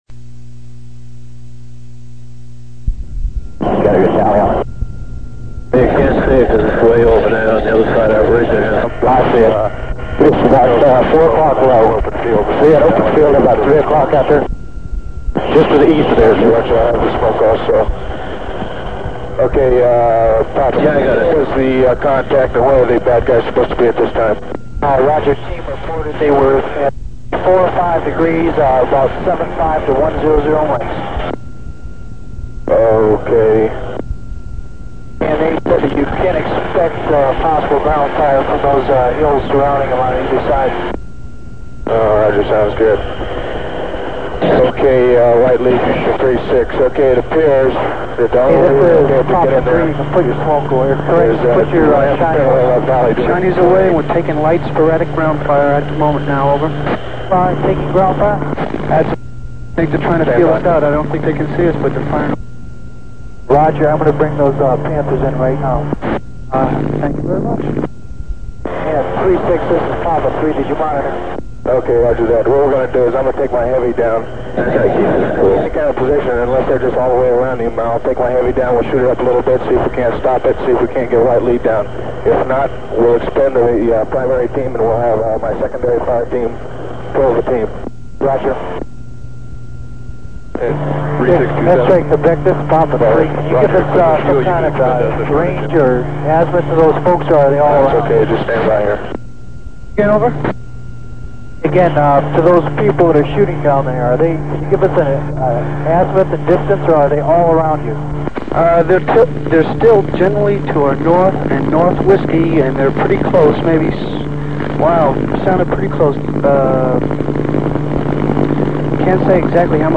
1970-71 Pleiku The following extraction mission was caught on tape by a Huey pilot. The recording graphically captures how a routine flight could quickly become a struggle for the survival of the men on the ground, and an all-out effort by the men in the air to do everything they could to support them - wishing they had done more.